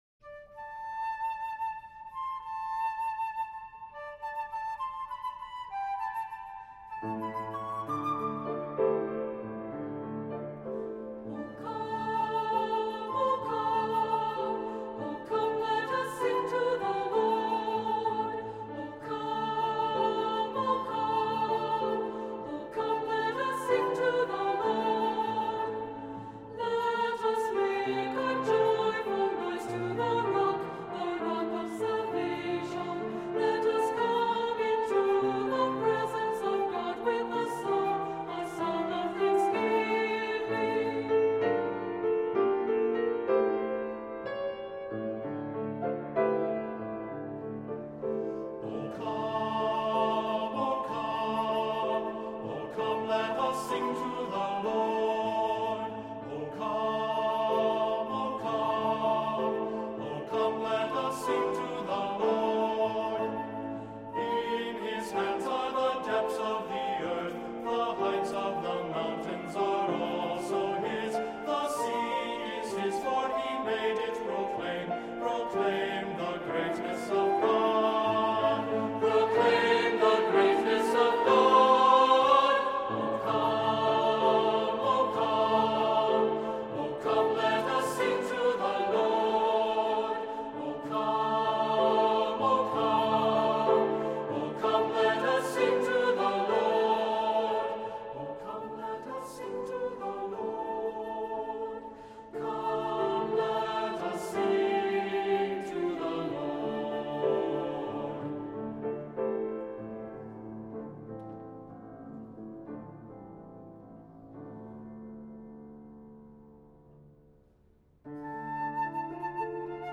Voicing: Two-part equal